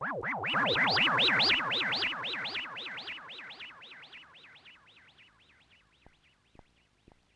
Microwave 1.wav